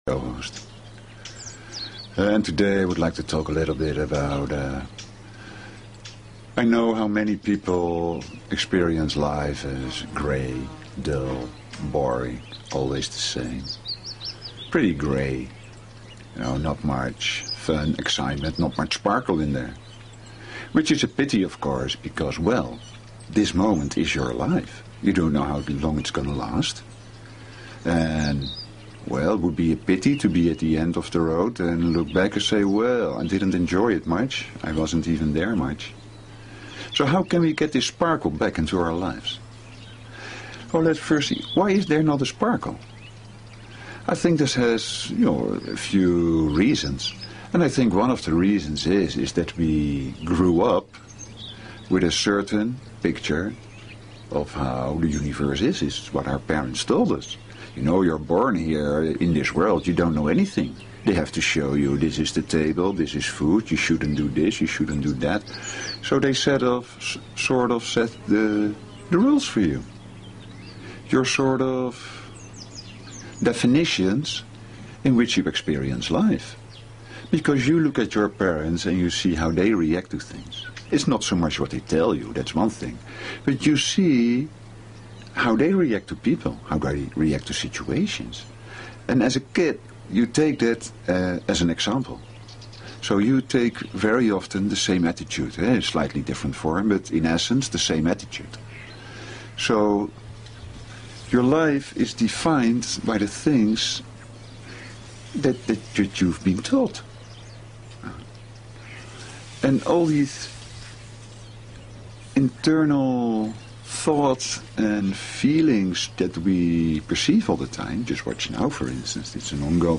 Talk Show Episode, Audio Podcast, Shuem_Soul_Experience and Courtesy of BBS Radio on , show guests , about , categorized as
The Meditation in the second part of the show is best listened through headphones and it is advised to stop doing other things.